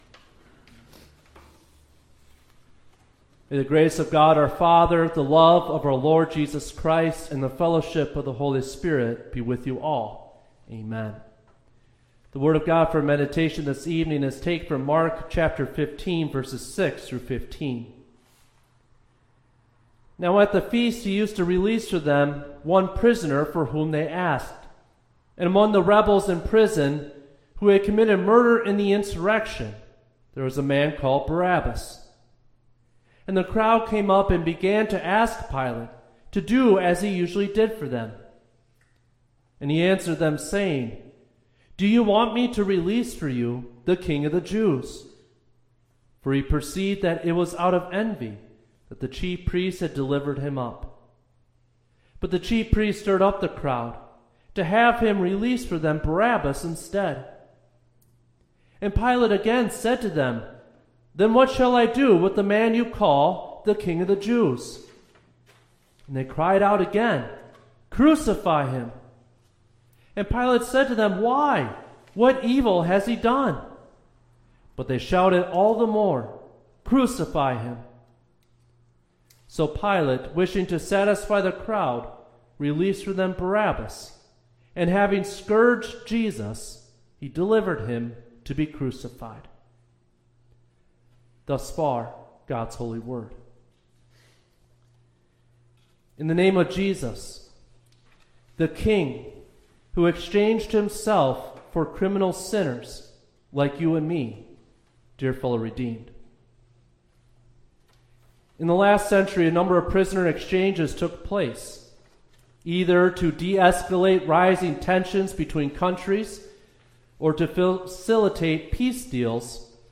Third-Midweek-Lenten-Service-_-March-4-2026.mp3